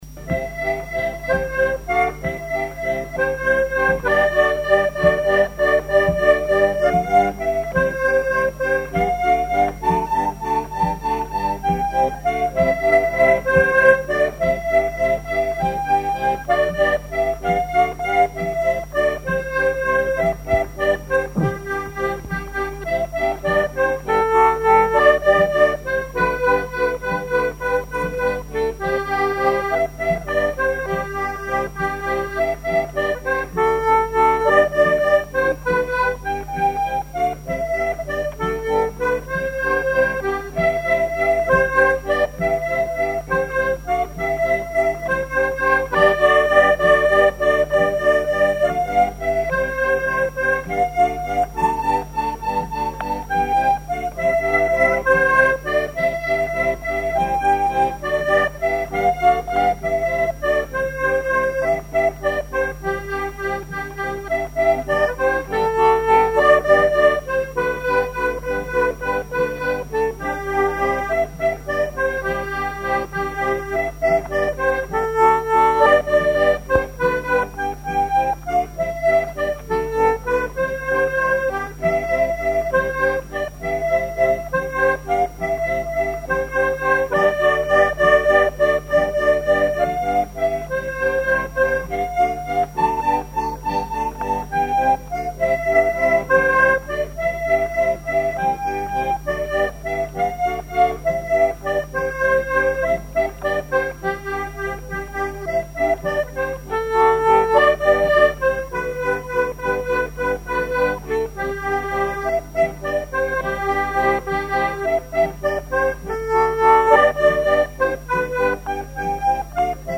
collectif de musiciens pour une animation à Sigournais
Pièce musicale inédite